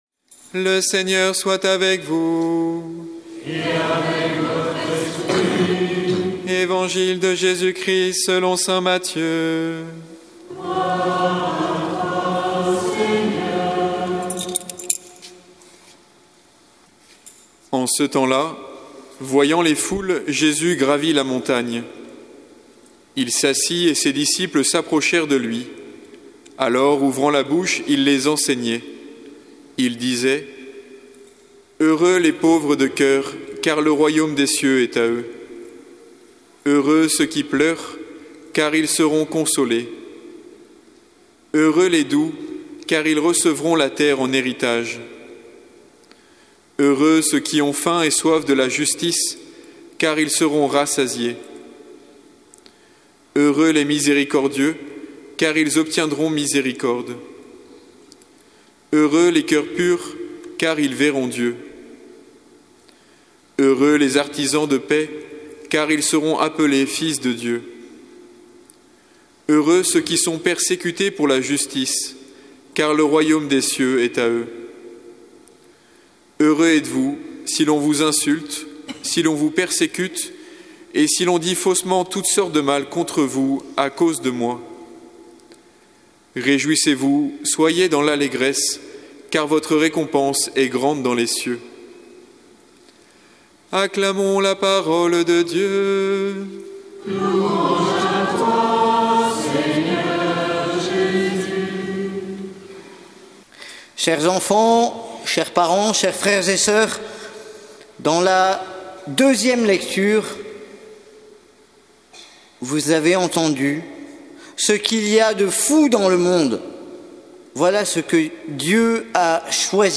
Évangile de Jésus Christ selon saint Matthieu avec l'homélie